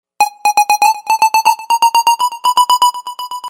电同步合成环120 BPM音乐
描述：电同步合成环120 BPM舒缓音乐。 使用软件合成器创建。
标签： 电音 合成器 循环 音乐圈 环境音 声音 背景声 节奏 合成器环路 韵律 120BPM 休息室 舒缓 节拍 绝妙 电子 音乐
声道立体声